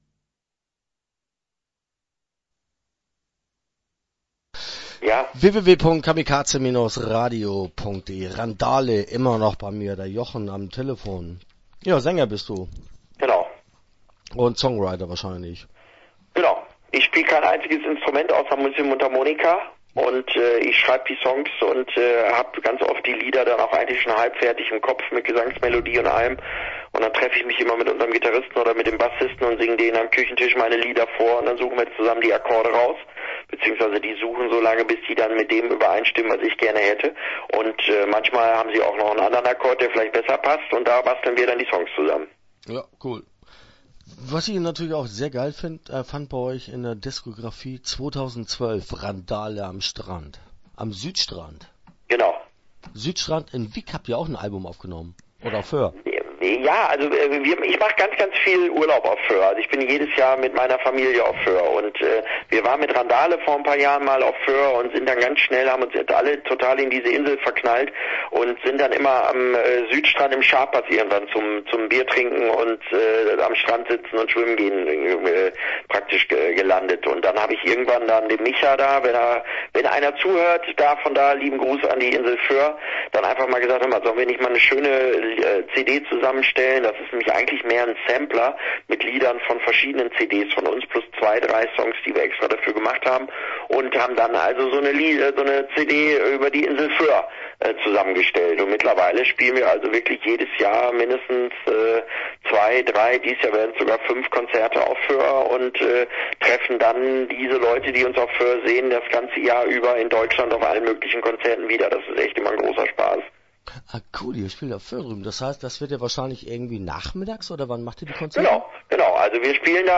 Randale - Interview Teil 1 (10:36)